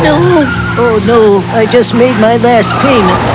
Voiced by Dan Castellaneta